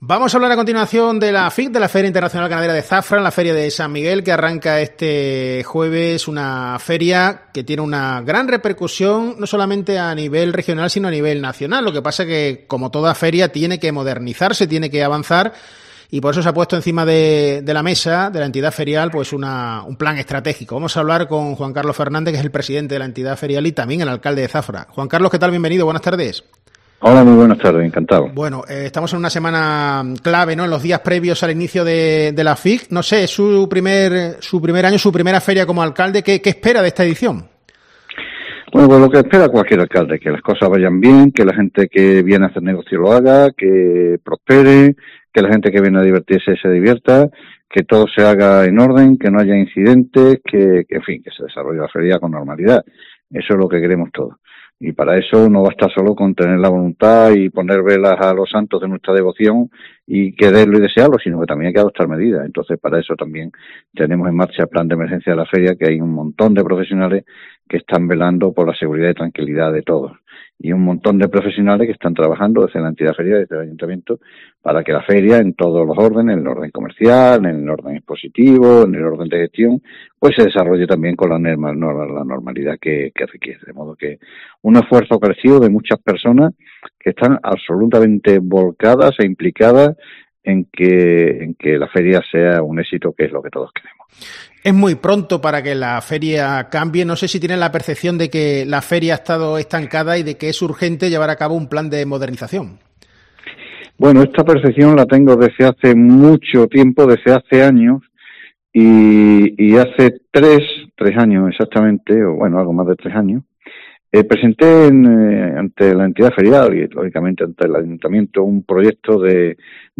El alcalde de zafra, Juan Carlos Fernández, en una entrevista a COPE se ha referido a la feria ganadera, que se inaugura este jueves, con más cabezas de ganado que el año pasado y con un 80 por ciento de vacuno.